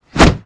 zombie_swing_1.wav